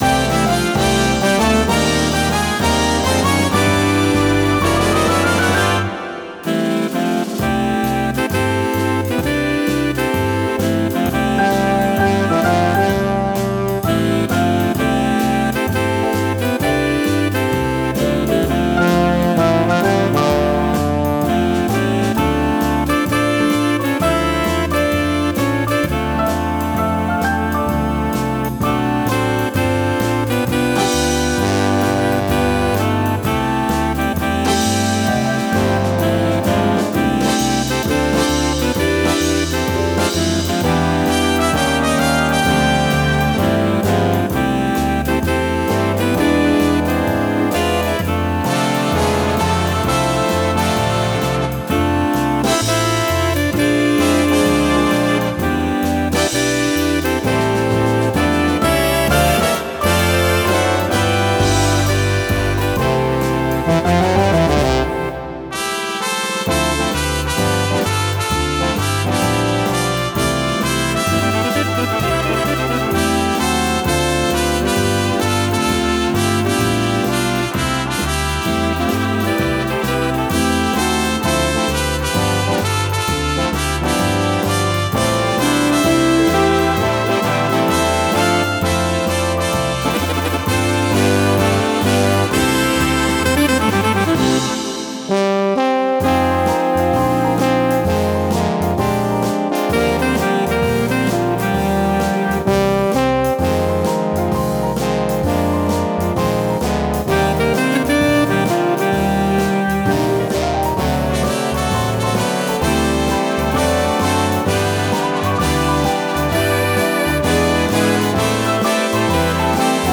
Big Band
Inštrumentalne
Swing